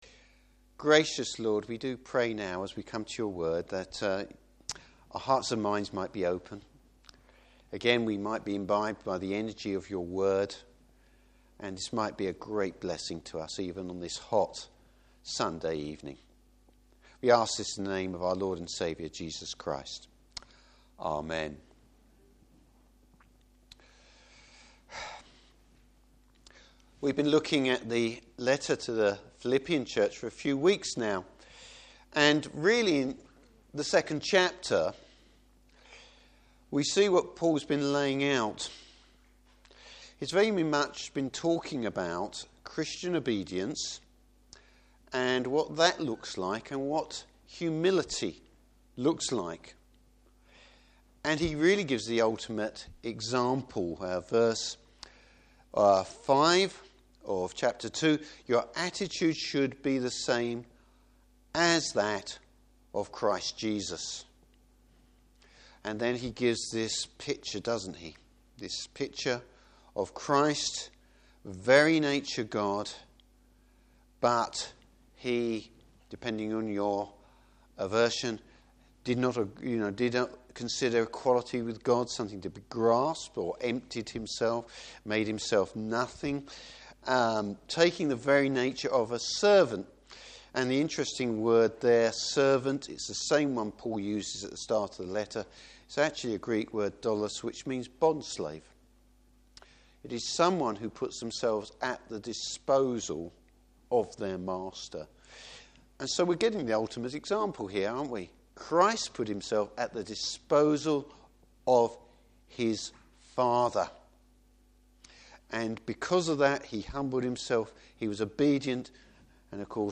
Service Type: Evening Service Two wonderful examples of partners in the Gospel.